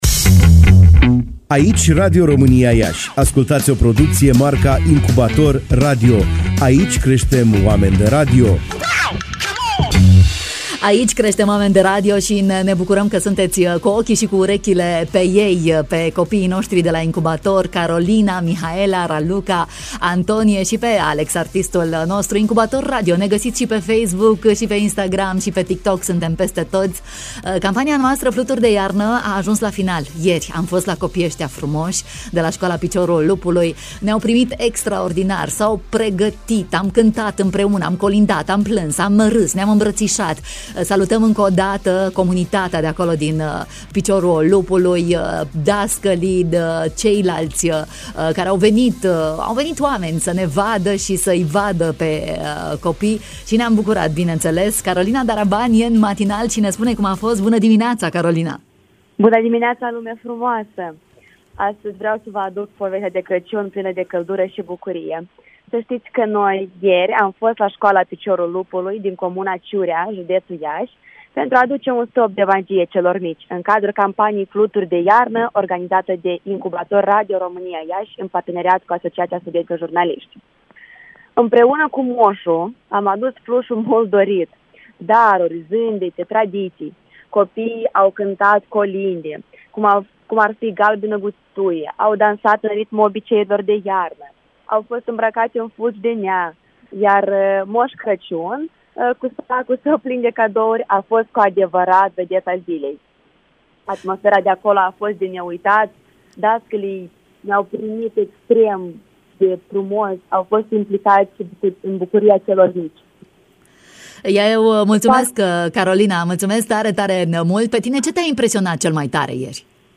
reporter ”Incubator” în direct la Bună Dimineața.